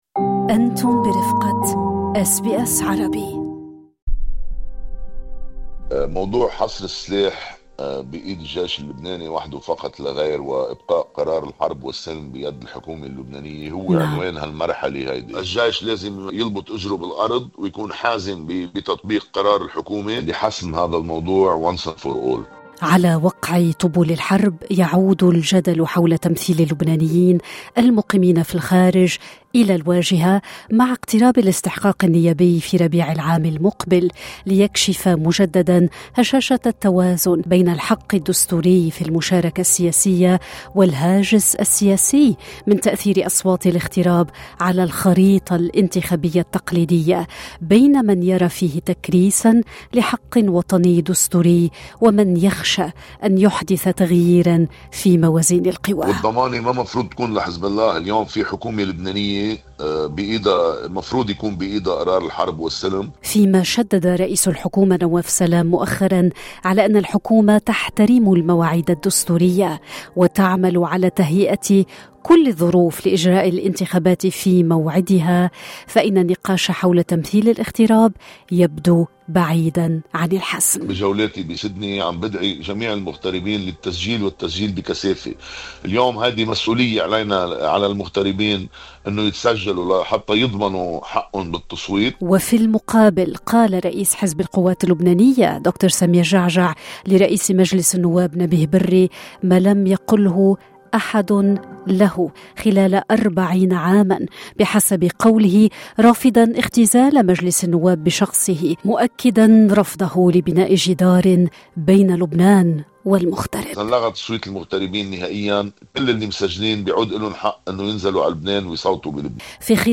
MP Elie Estephan speaks about the representation of the Lebanese diaspora in parliamentary elections between constitutional rights and political concerns as he visits Sydney.